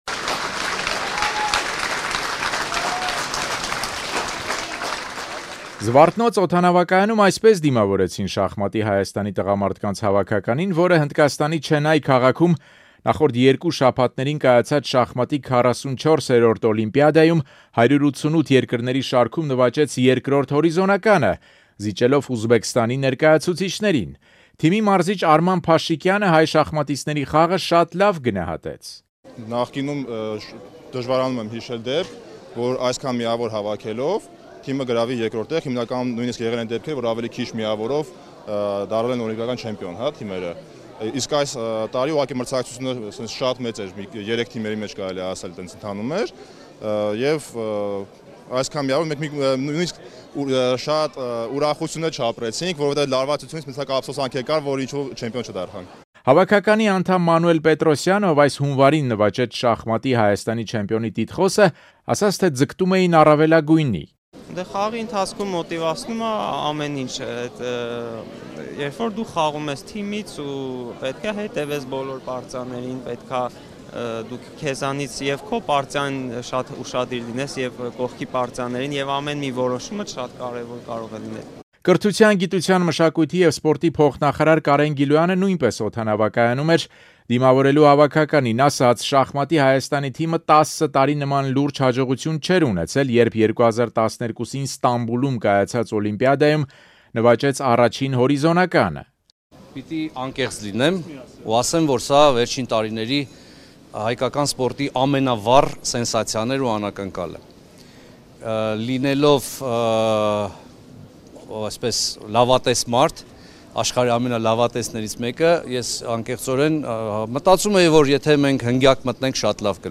Շախմատի Հայաստանի տղամարդկանց հավաքականին դիմավորեցին ծափողջույններով